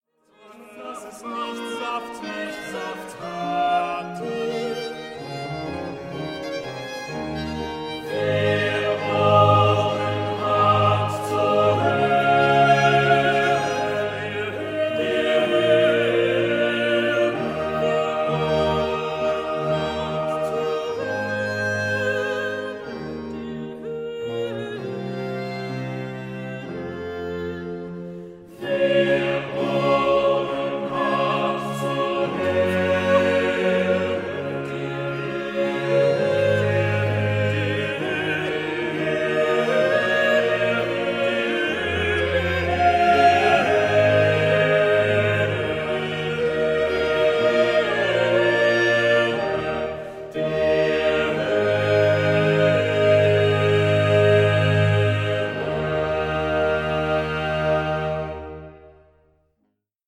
Instrumentalisten